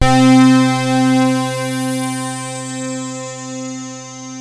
KORG C4  1.wav